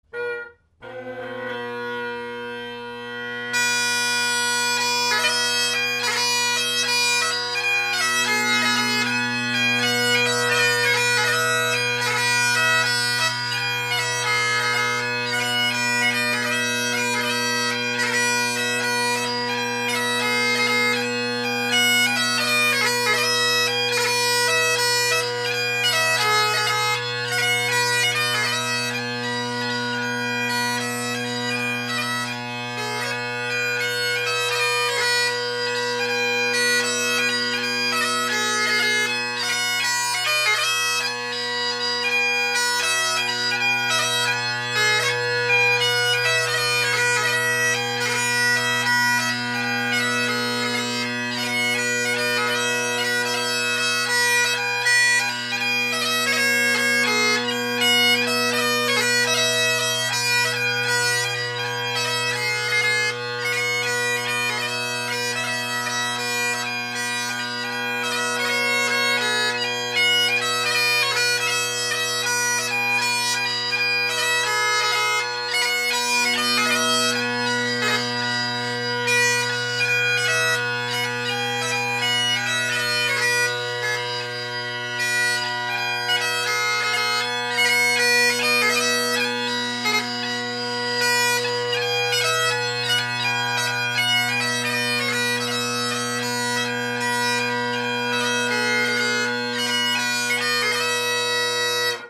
Great Highland Bagpipe Solo
It was okay for light music, but they’re definitely showing their age.
Gellaitry’s with old Selbie’s: